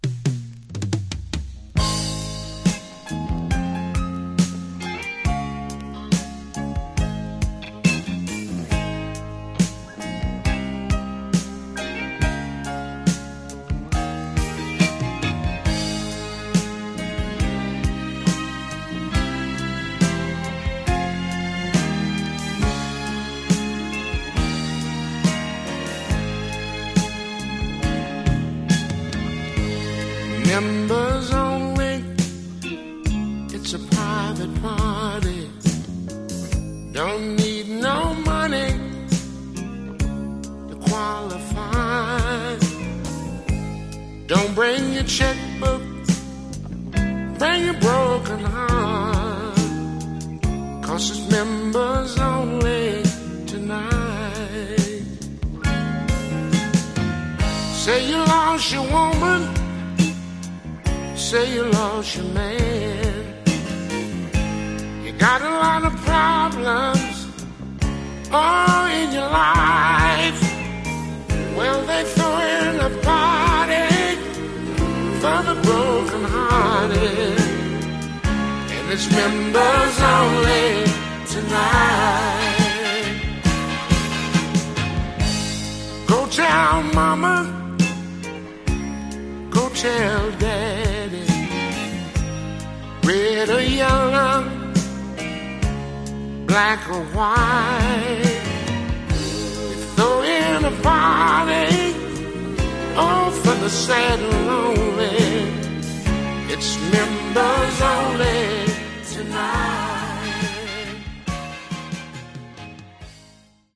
Genre: Deep Soul